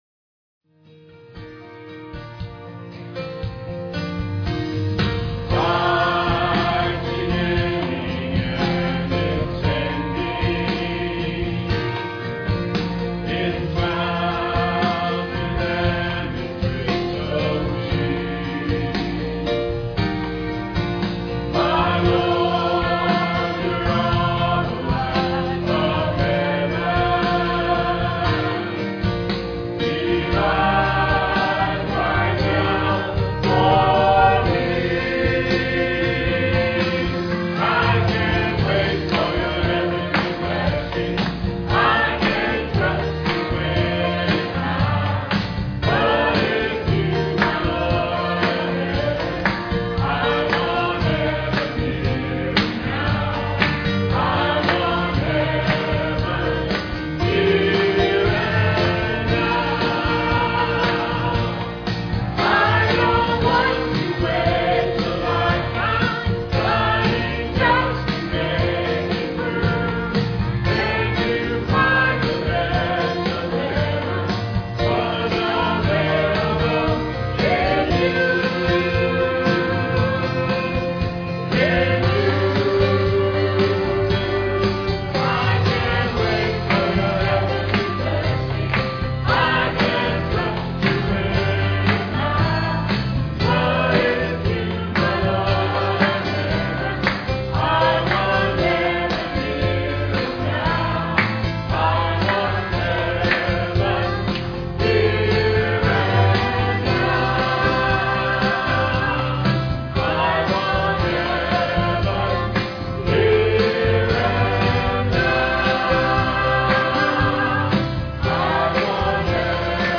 Piano and organ offertory
Electric guitar